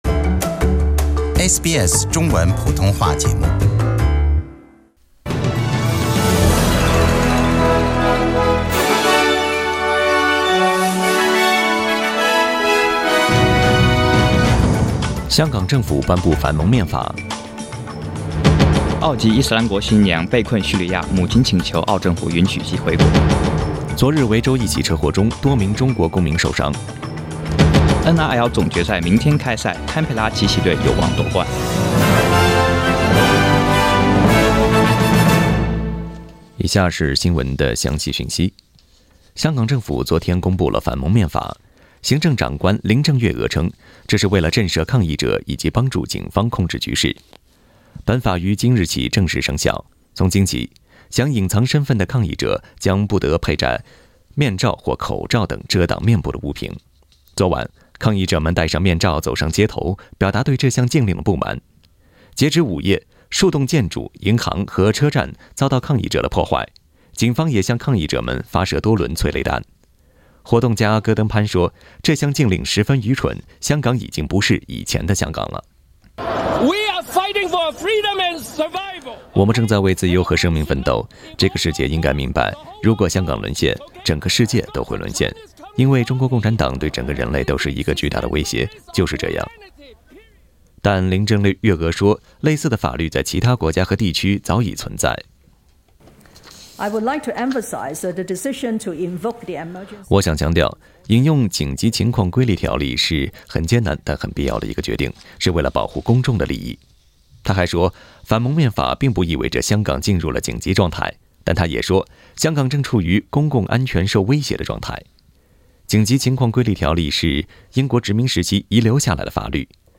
SBS 早新闻（10月5日）